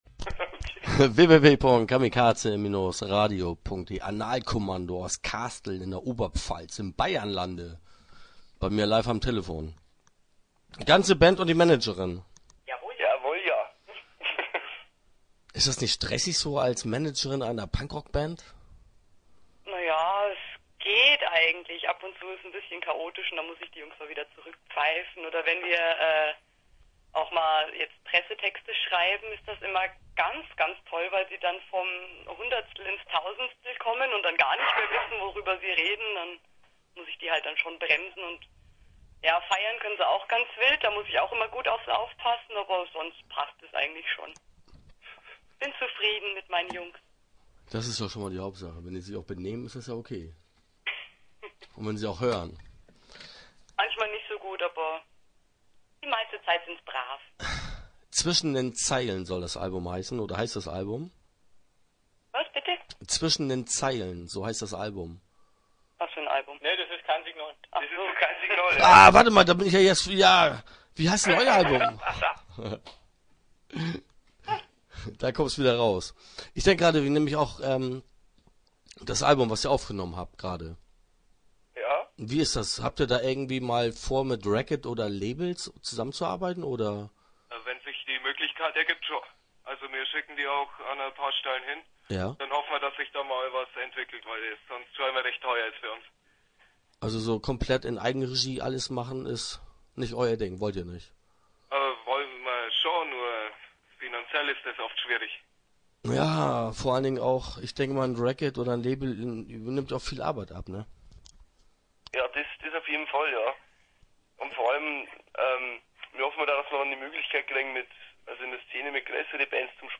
Interview Teil 1 (9:20)